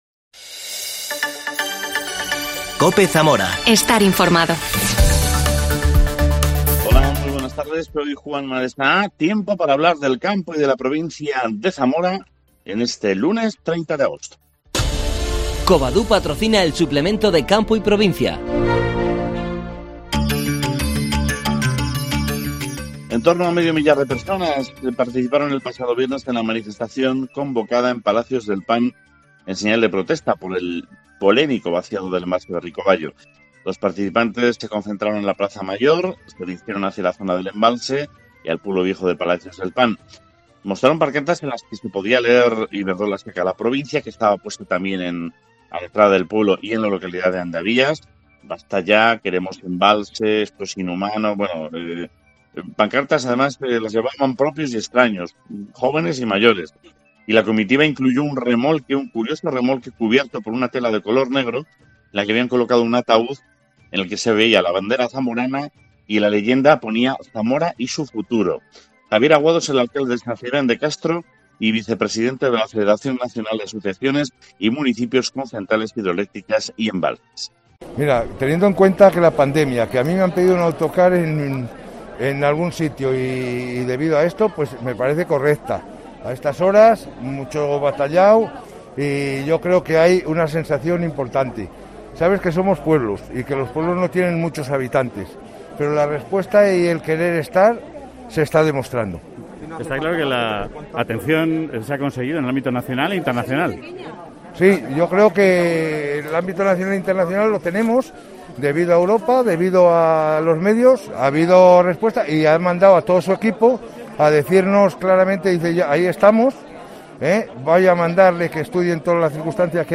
Hablamos con Javier Aguado, alcalde de San Cebrian de Castro y vicepresidente de la Federación Nacional de Asociaciones y Municipios con Centrales Hidroeléctricas y Embalses.